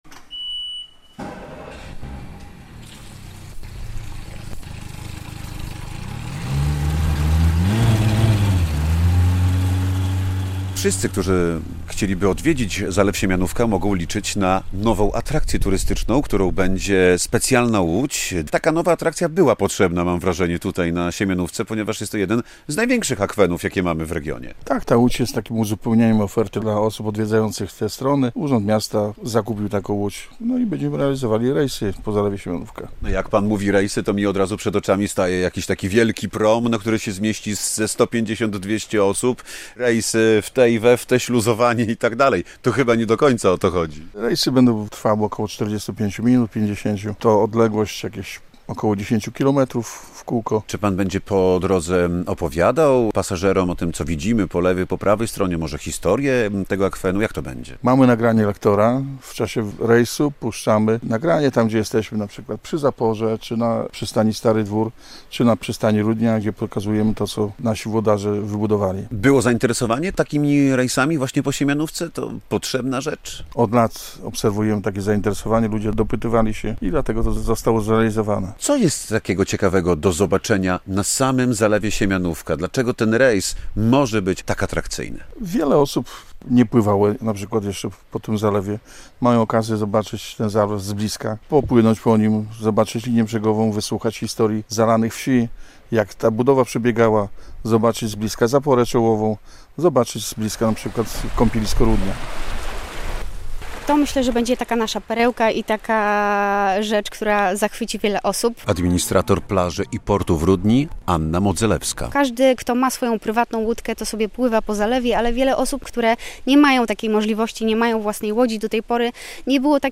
Wędkowanie nad Zalewem Siemianówka - relacja